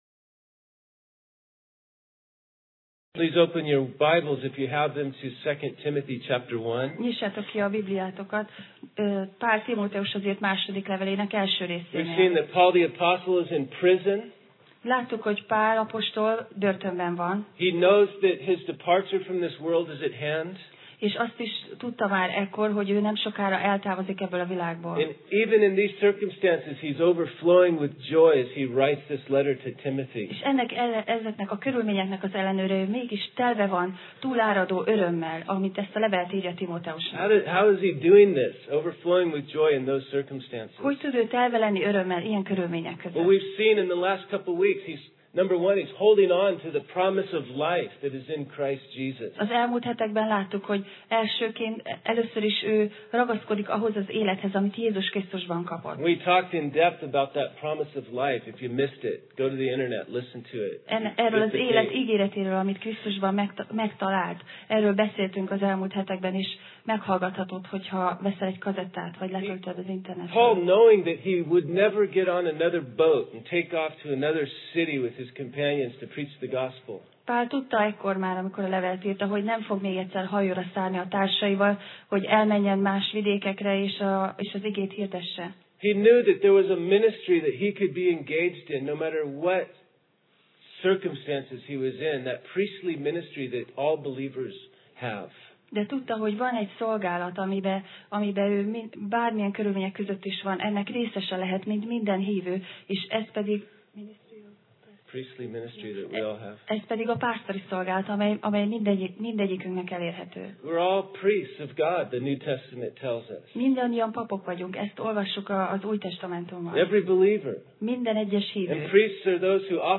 2Timóteus Passage: 2Timóteus (2Timothy) 1:6-7 Alkalom: Vasárnap Reggel